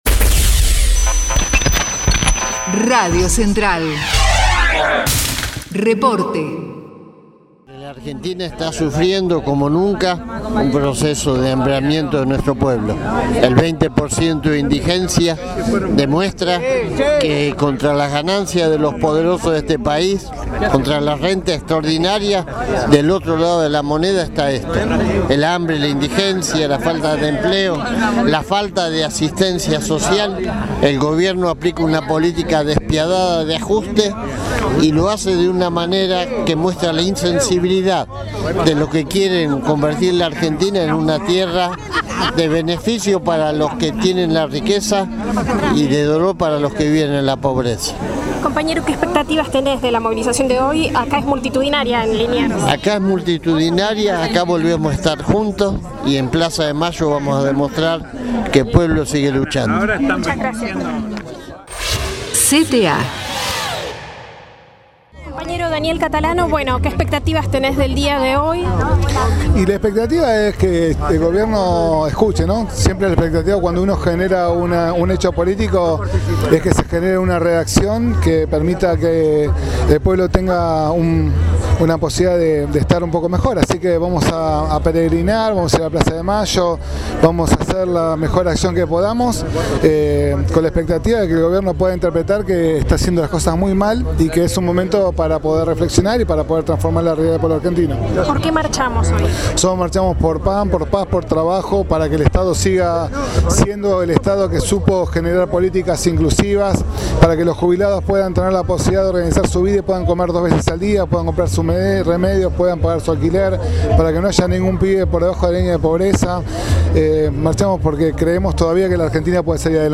marcha_san_cayetano_plaza_de_mayo_agosto7.mp3